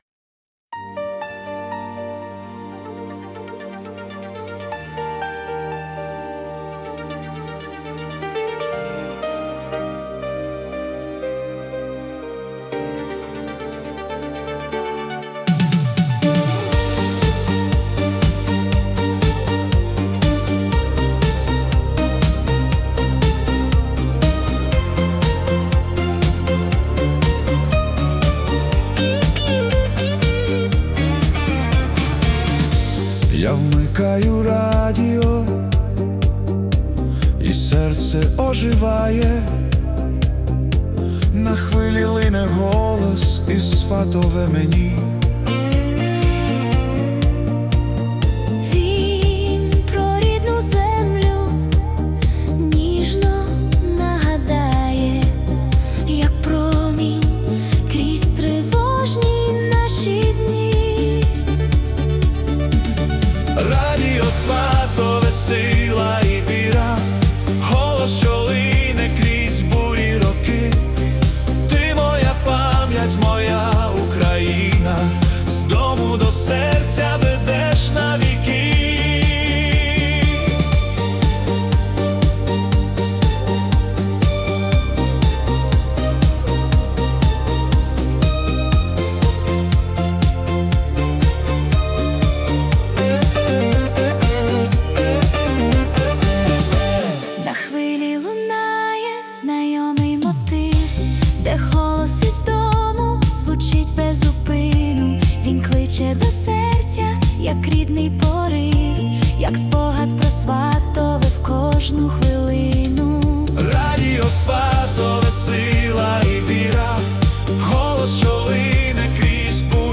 🎵 Жанр: Ukrainian Disco / Retro Pop
це запальне та ностальгійне ретро-диско (120 BPM)
Чудова музика для підняття настрою та зміцнення духу.